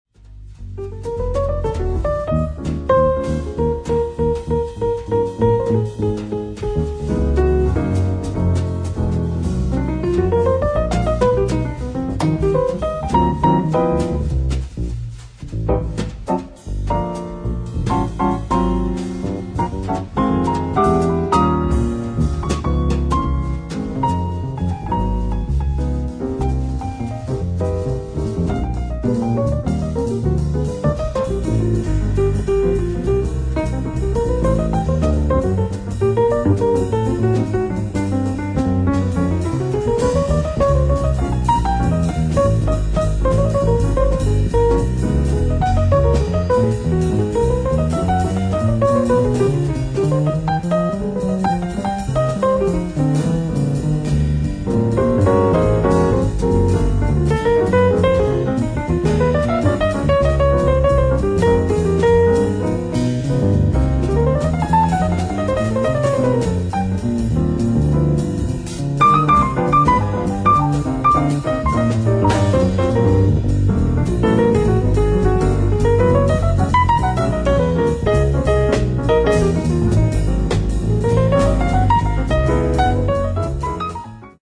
ライブ・アット・モルデ・ジャズフェスティバル、モルデ、ノルウェー 07/20/2000
超美音ピアノ・トリオ！！
※試聴用に実際より音質を落としています。